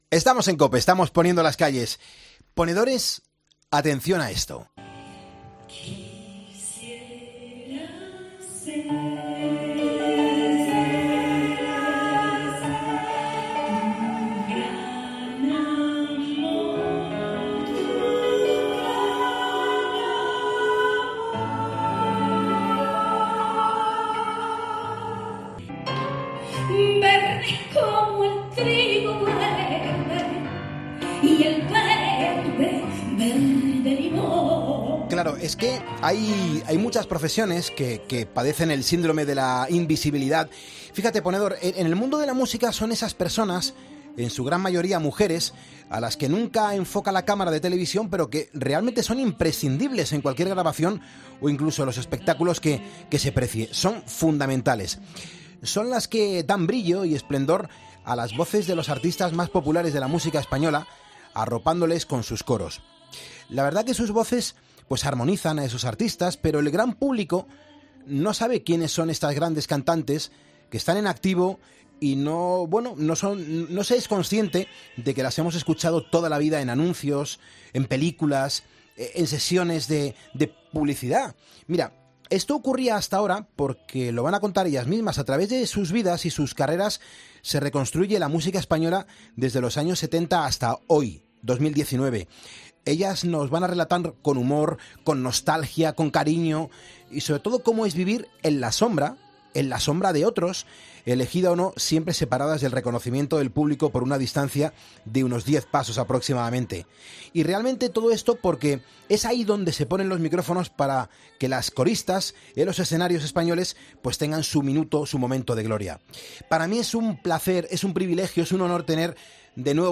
Poniendo las Calles Escucha la entrevista